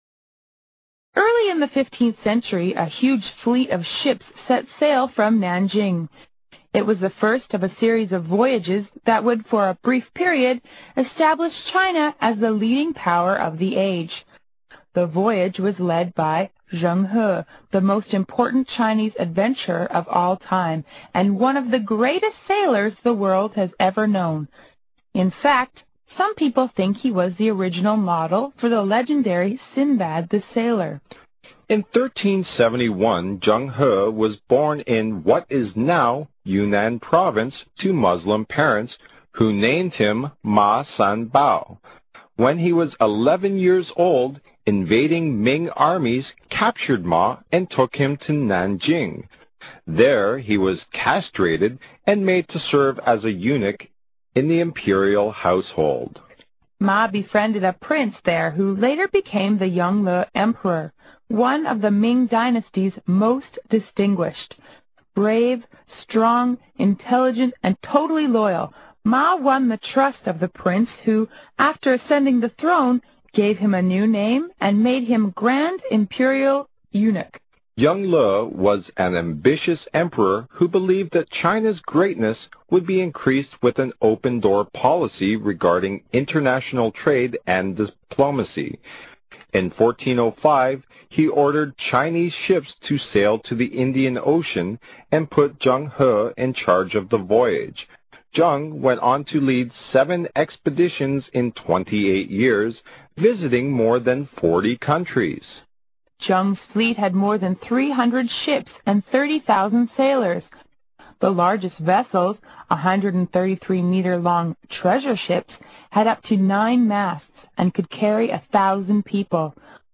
有声英文阅读-郑和下西洋(三保太监的不朽航程) 听力文件下载—在线英语听力室